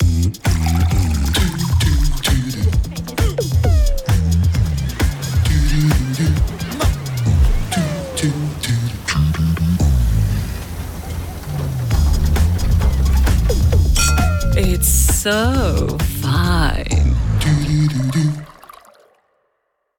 Fun and flirty - Finlandia Vodka ad